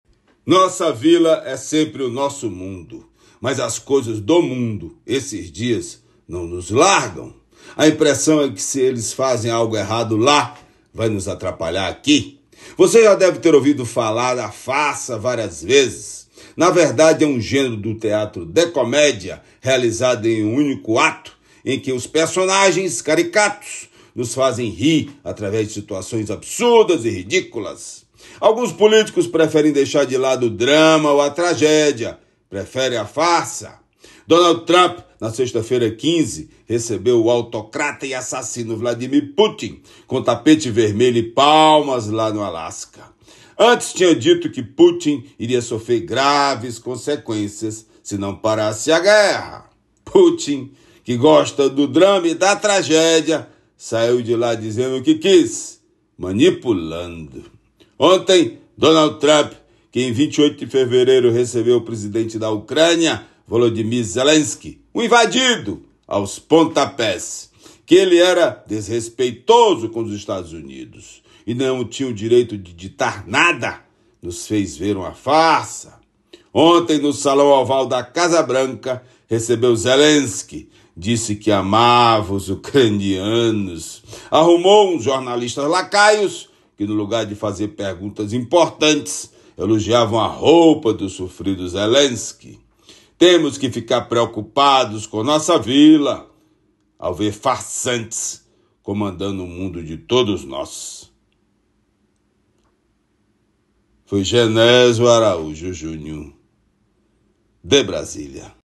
Comentário do jornalista